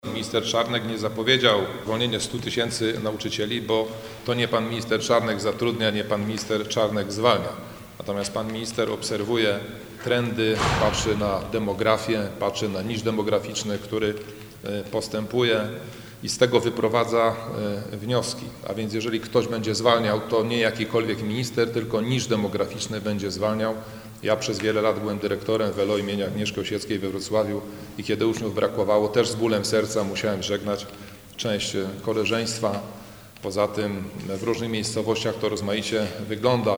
Zapytaliśmy się także, o ostatnią wypowiedź Ministra Przemysława Czarnka w sprawie zwolnień nauczycieli. Mówi Roman Kowalczyk.